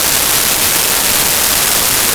Shower Constant
Shower Constant.wav